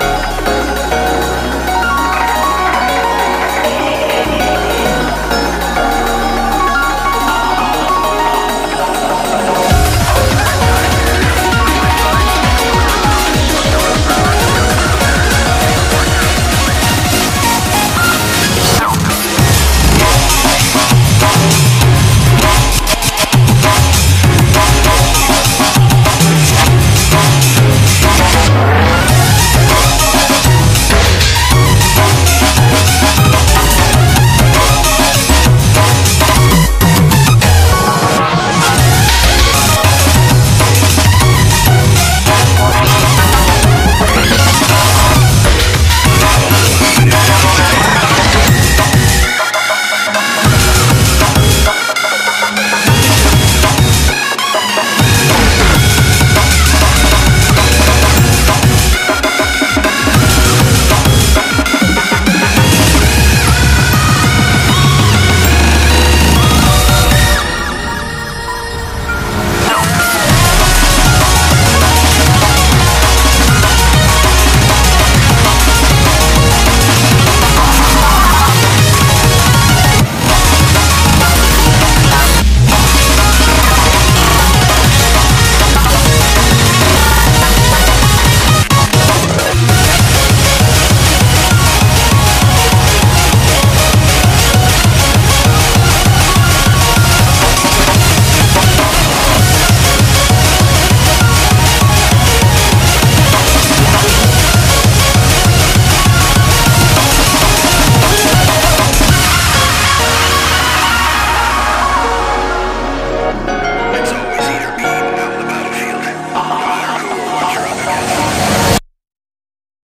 BPM99-396
MP3 QualityMusic Cut